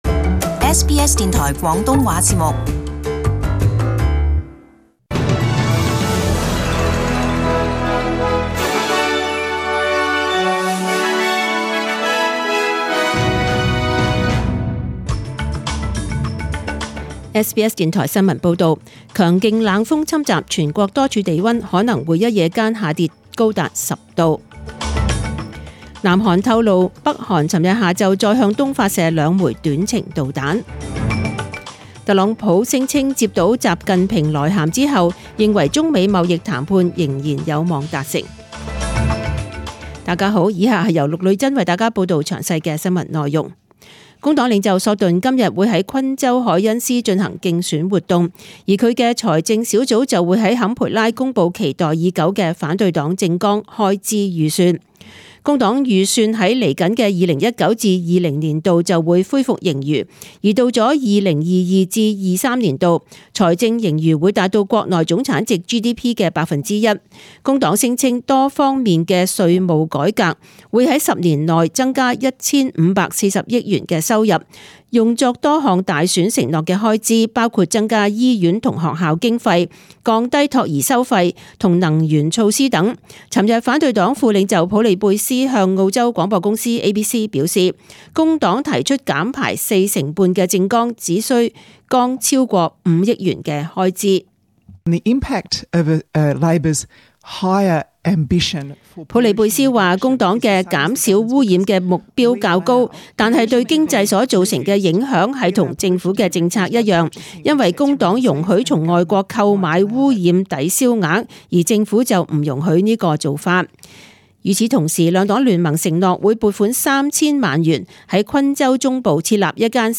Chinese (Cantonese) News Source: SBS News